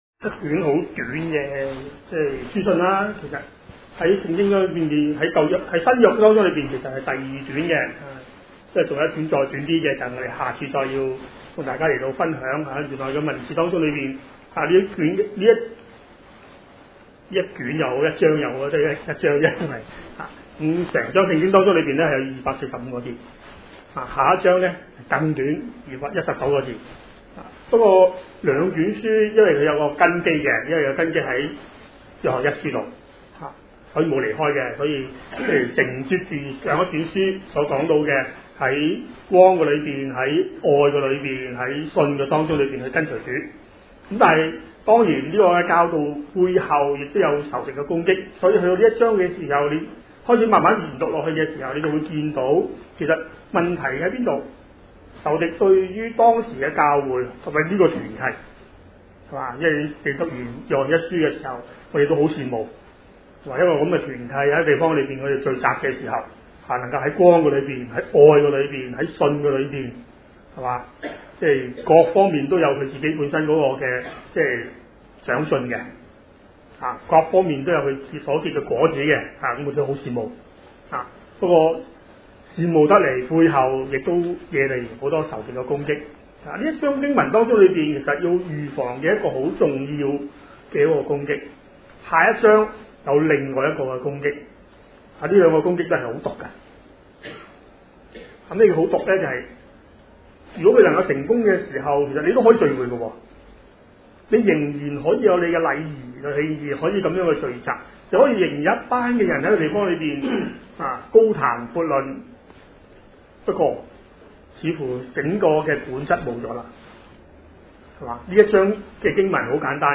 地點：荃灣基督徒聚會所